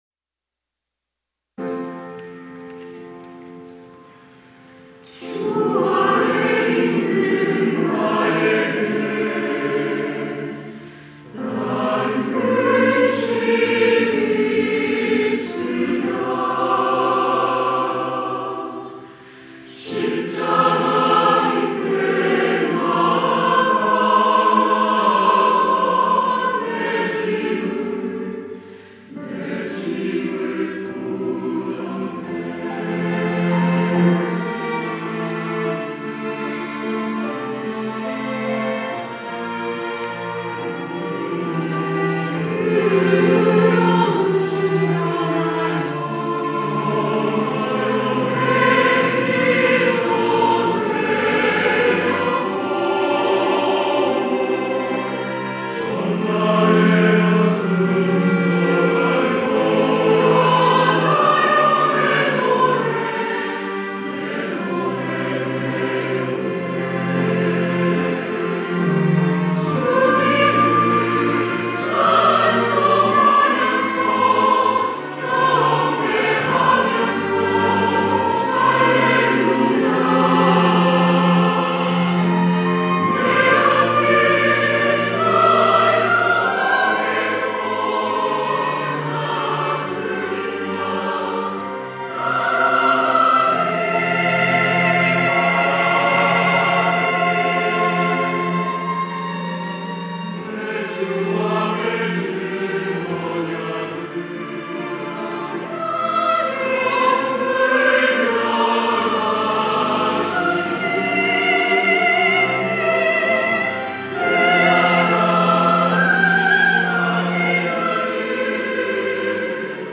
할렐루야(주일2부) - 주 안에 있는 나에게
찬양대